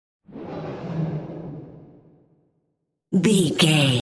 Sound Effects
Atonal
ominous
haunting
eerie